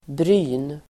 Uttal: [bry:n]